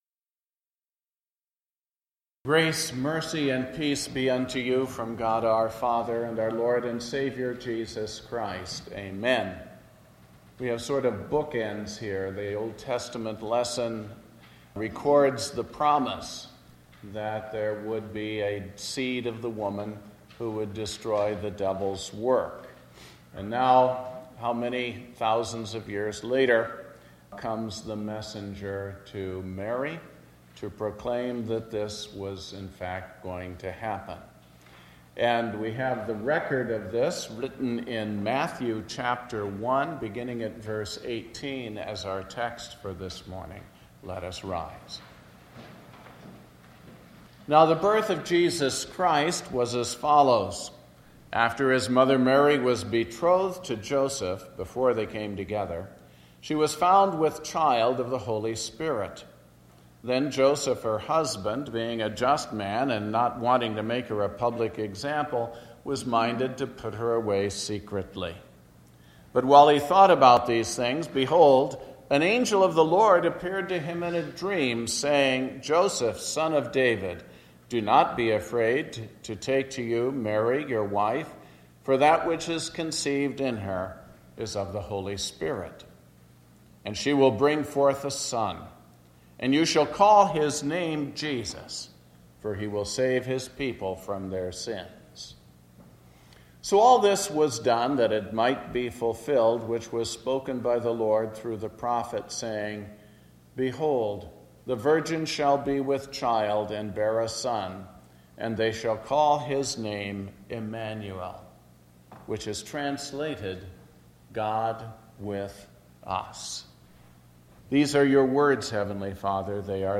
Sermon based on Matthew 1:18-23